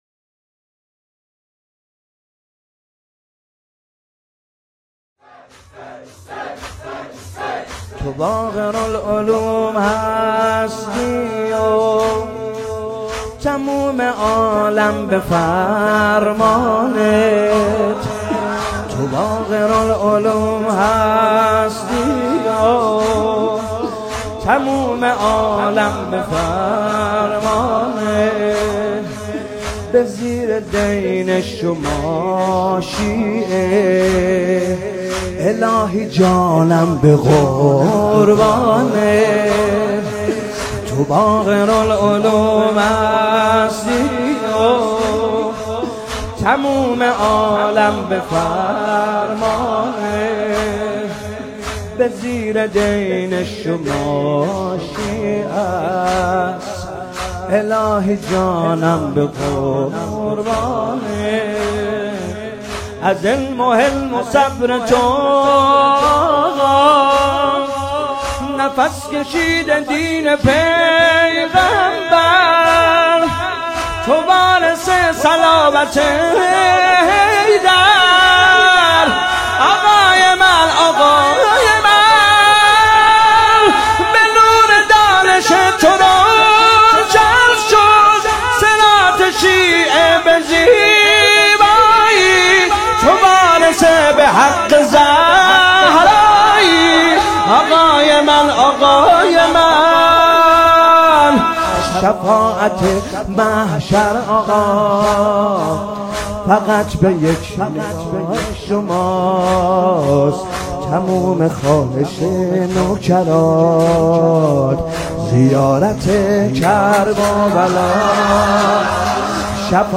مداحی «تو باقرالعلوم هستی»
ویژه شهادت امام محمد باقر علیه السلام با نوای دلنشین